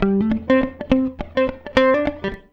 104FUNKY 13.wav